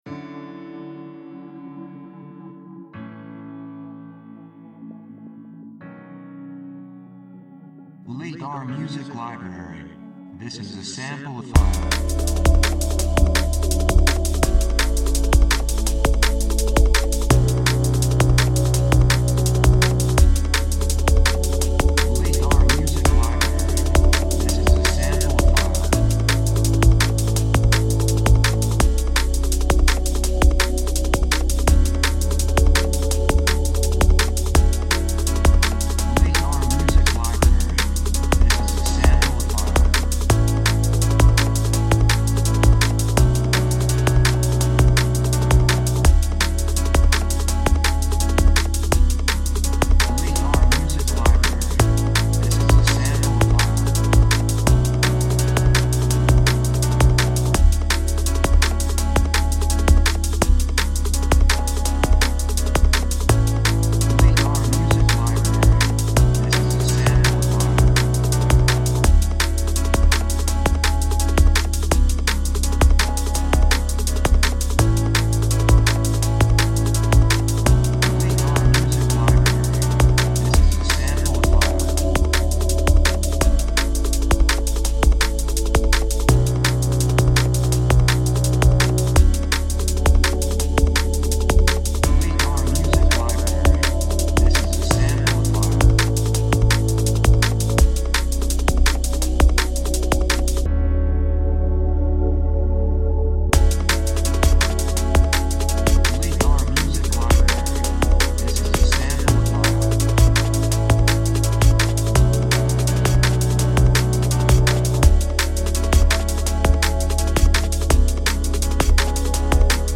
2:59 167 プロモ, エレクトロニック
雰囲気エネルギッシュ, 幸せ, せわしない, 高揚感, 決意, 夢のような, 喜び
楽器シンセサイザー
サブジャンルドラムンベース
テンポとても速い